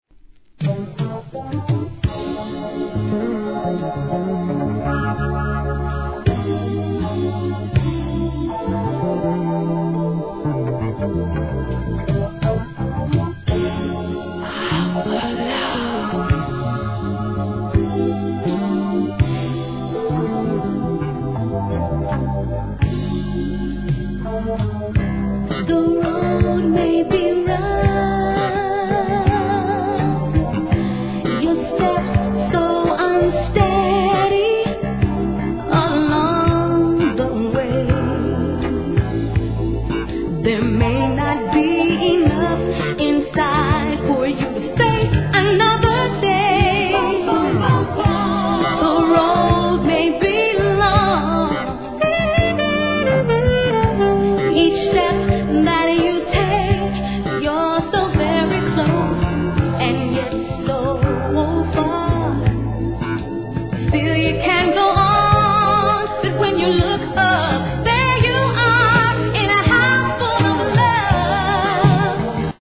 ¥ 770 税込 関連カテゴリ SOUL/FUNK/etc...